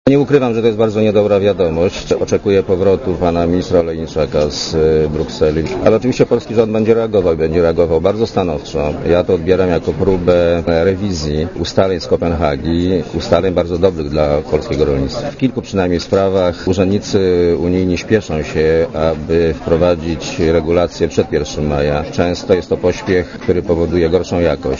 Komentarz audio (100Kb)
"Odbieram to jako próbę rewizji ustaleń z Kopenhagi - bardzo dobrych dla polskiego rolnictwa" - powiedział Miller dziennikarzom w Sejmie.